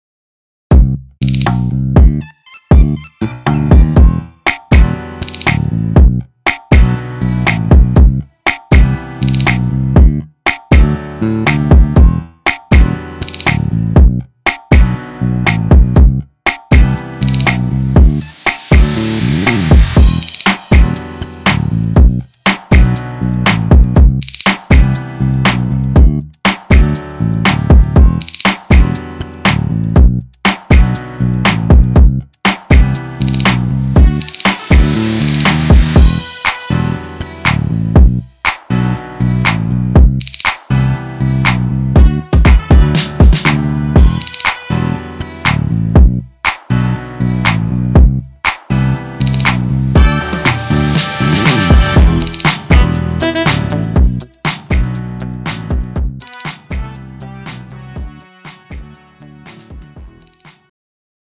장르 가요